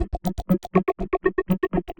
Index of /musicradar/rhythmic-inspiration-samples/120bpm